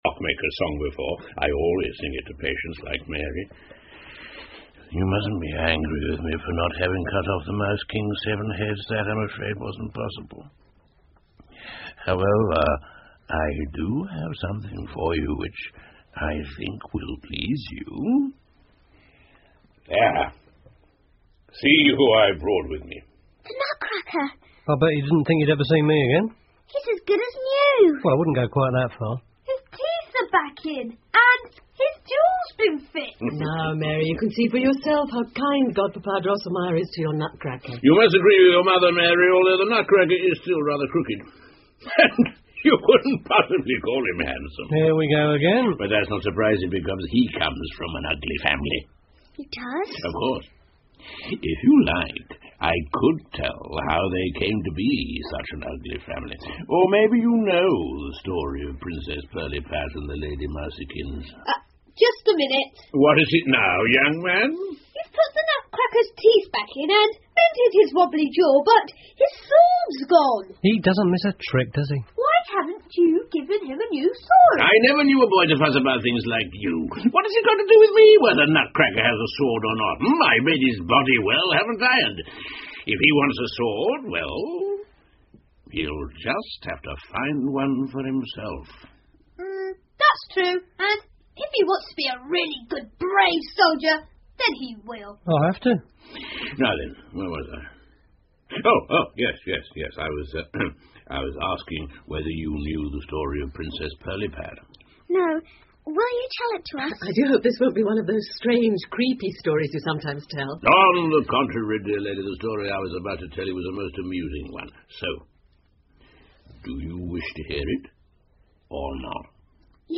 胡桃夹子和老鼠国王 The Nutcracker and the Mouse King 儿童广播剧 12 听力文件下载—在线英语听力室